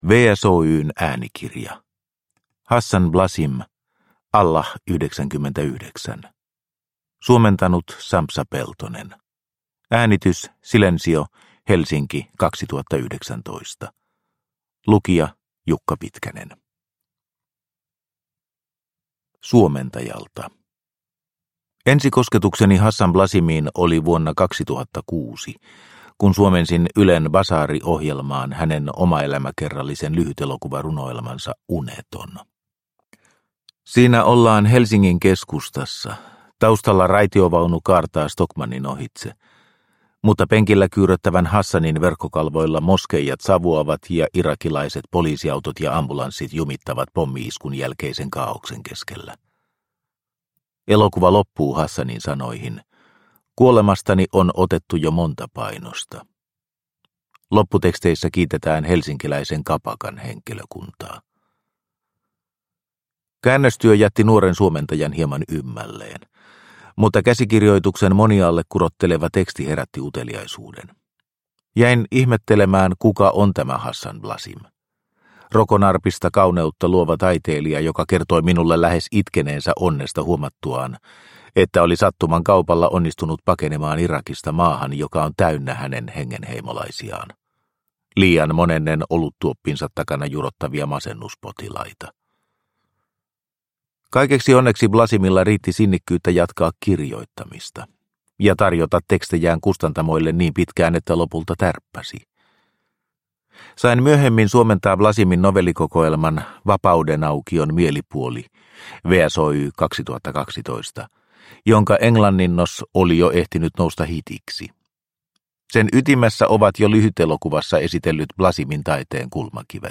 Allah99 – Ljudbok – Laddas ner